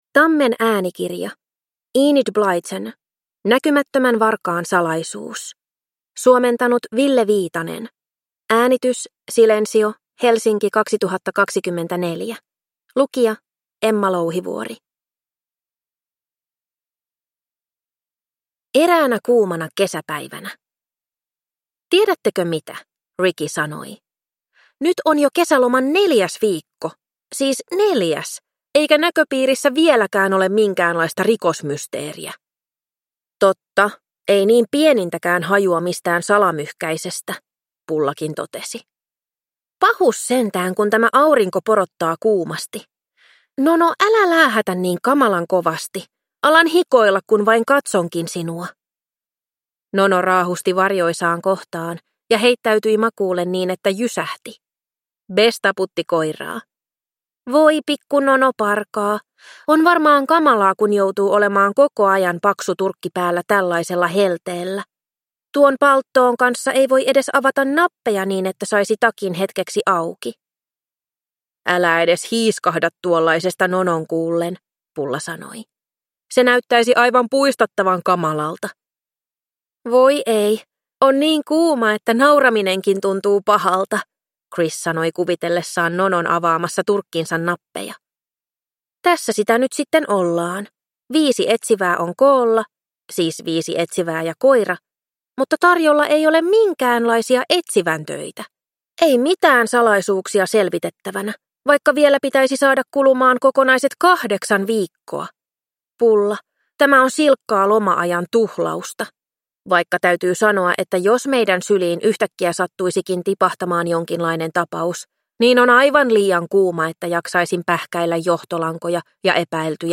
Näkymättömän varkaan salaisuus – Ljudbok